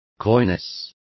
Complete with pronunciation of the translation of coyness.